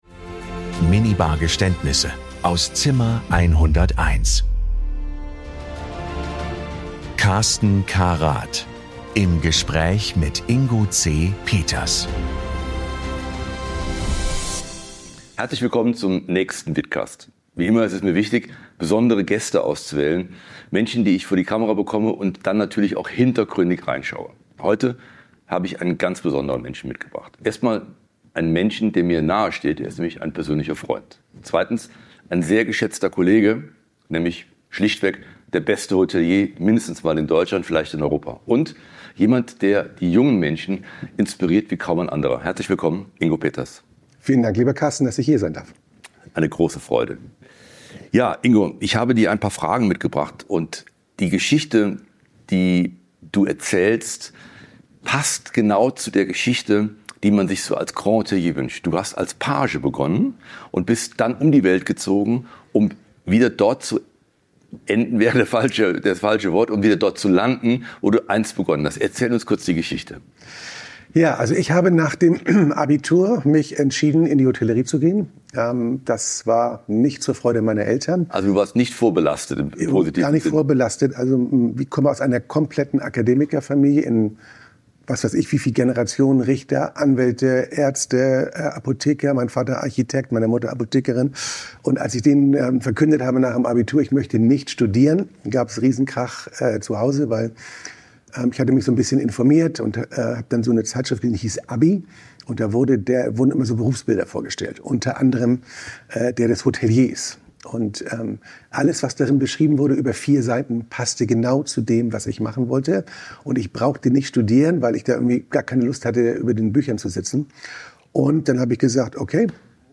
Ein Gespräch über Verantwortung an der Spitze, exzellente Servicekultur und die Frage, wie man ein Grand Hotel über Jahrzehnte hinweg auf höchstem Niveau positioniert.